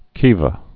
(kēvə)